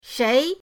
shei2.mp3